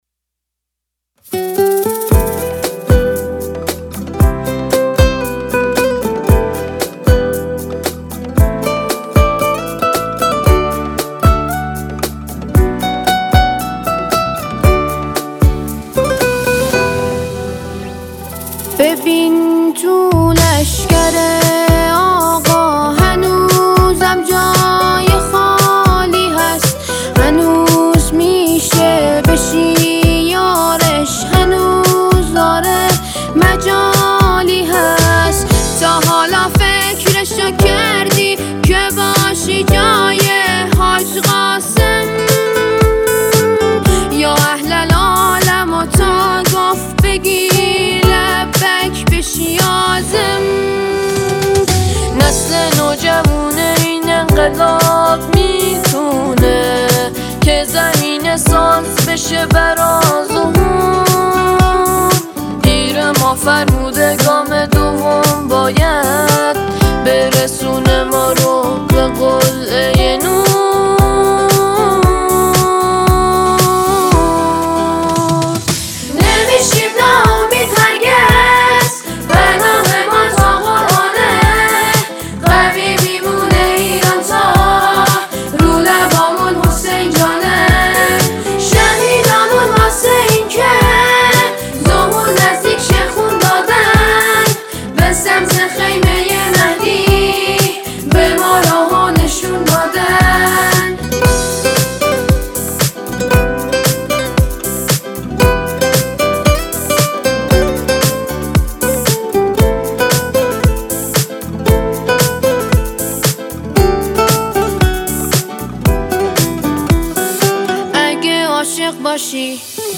قطعه سرود
با حضور نوجوانان عضو جلسه آموزش قرآن شهر نهاوند